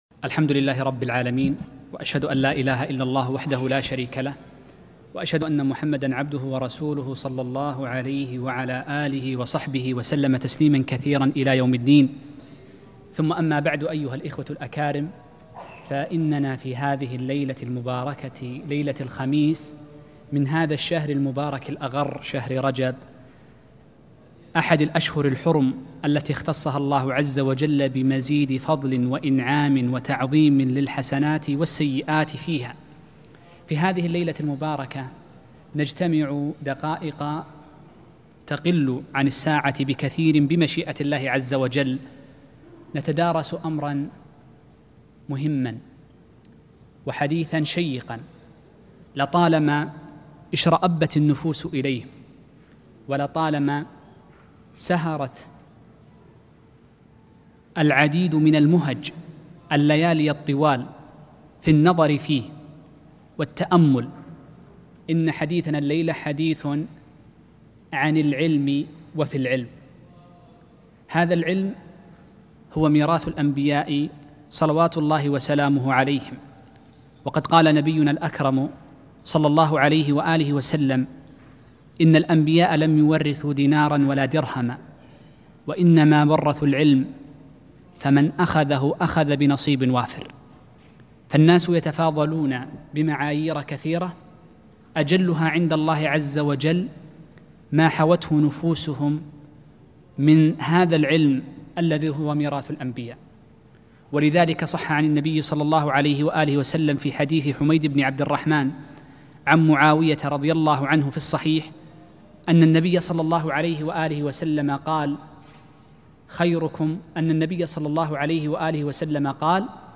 محاضرة - معوقات طلب العلم